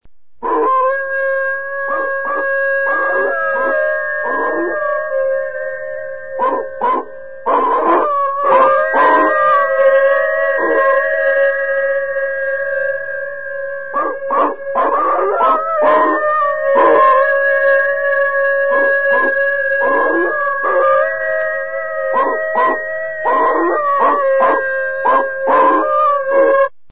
SONIDOS DE TERROR AHULLIDO DE LOBOS
Ambient sound effects
SONIDOS_DE_TERROR_ahullido_de_lobos.mp3